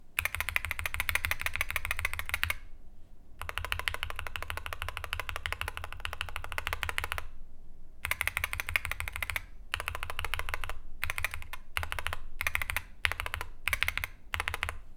Im bardziej zbliżymy do nich ucho, tym lepiej usłyszymy, że to po prostu metaliczny pogłos.
Powyższy plik dźwiękowy przedstawia brzmienie klawiatury w domyślnej formie, a poniższy – porównanie niezmodyfikowanego przełącznika i takiego po nasmarowaniu sprężynki.
recenzja-Wooting-60HE-porownanie.mp3